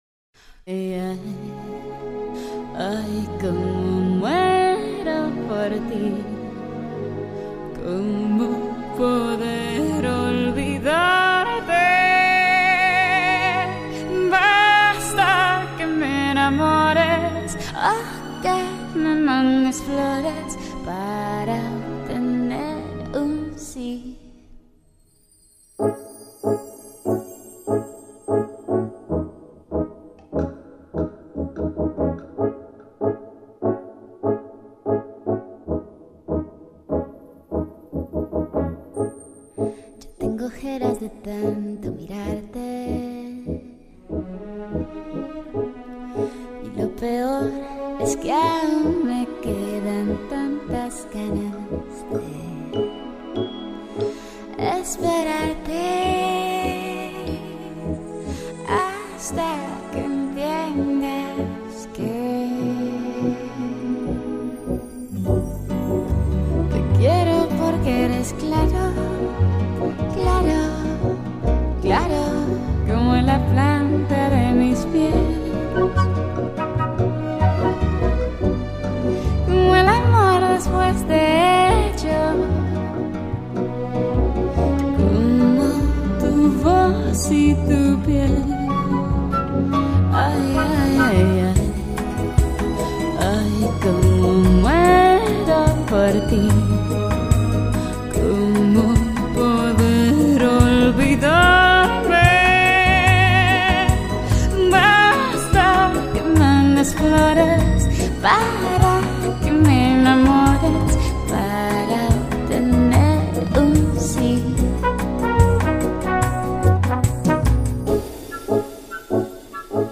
音樂類型: Pop